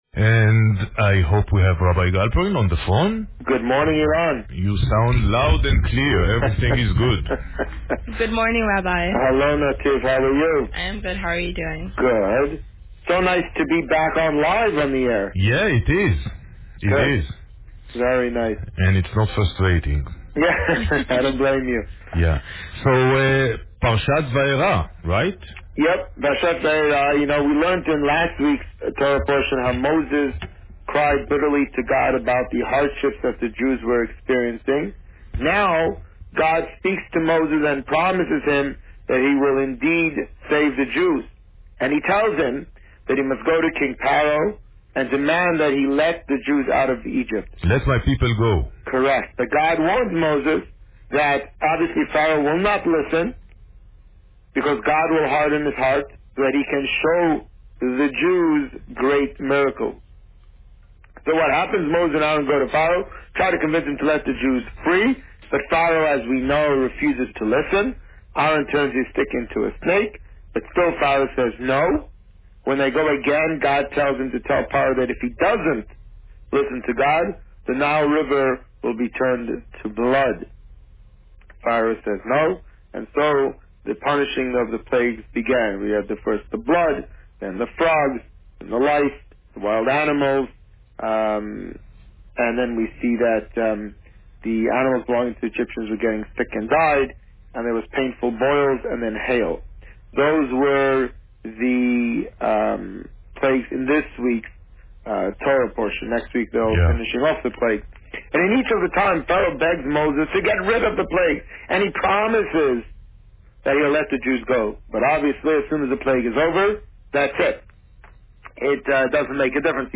This week, the Rabbi spoke about Parsha Va'eira. Listen to the interview here.